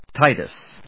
Ti・tus /tάɪṭəs/
• / tάɪṭəs(米国英語)